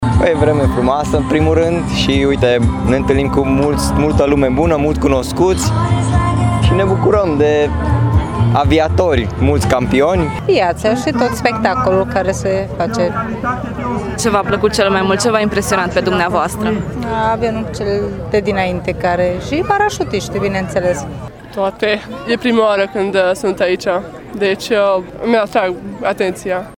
Acestea sunt doar câteva dintre atracțiile celei de-a VII – a ediții a mitingului aviatic „Sky is not the limit”, care a avut loc sâmbătă.